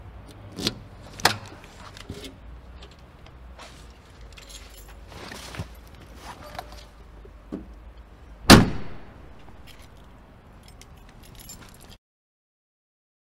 Tổng hợp tiếng Đóng/ mở Cốp xe
Thể loại: Tiếng xe cộ
Description: Tổng hợp tiếng đóng/mở cốp xe ô tô – bộ sưu tập âm thanh thực tế ghi lại nhiều kiểu đóng và mở cốp xe, từ tiếng “cạch” nhẹ nhàng đến tiếng “rầm” mạnh mẽ, giúp tái hiện trung thực từng thao tác.
tong-hop-tieng-dong-mo-cop-xe-www_tiengdong_com.mp3